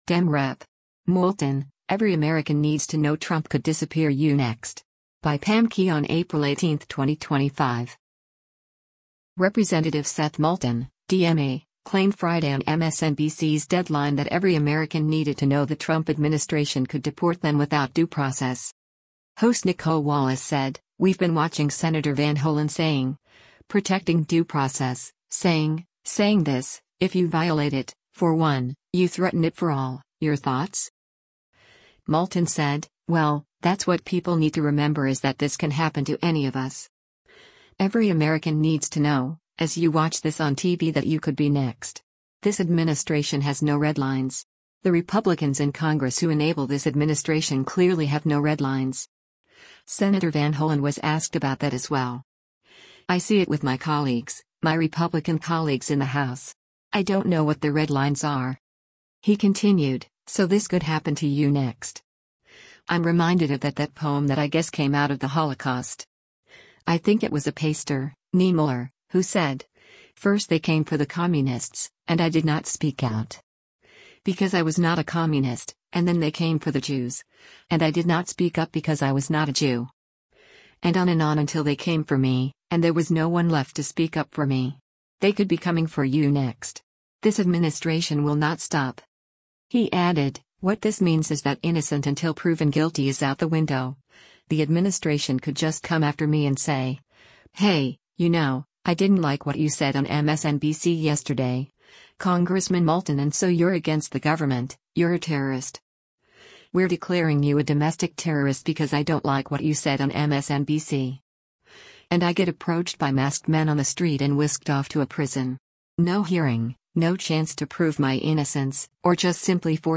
Representative Seth Moulton (D-MA) claimed Friday on MSNBC’s “Deadline” that every American needed to know the Trump administration could deport them without due process.